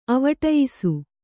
♪ avaṭayisu